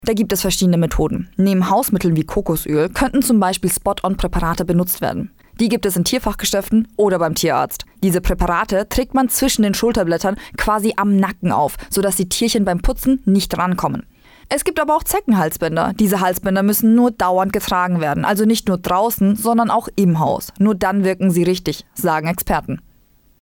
Interview: Zecken bei Tieren - so kann man sich schützen - PRIMATON